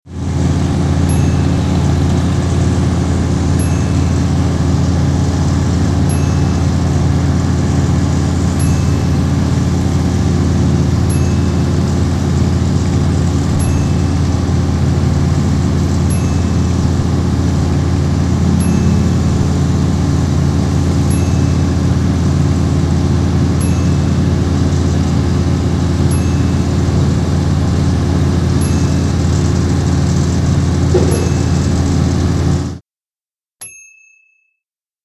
18 Wheeler Semi Truck Idling Sound Effect
Big 18 wheeler idling at a truck stop or intersection. Very clean recording with no unwanted noise.
18WheelerIdling.mp3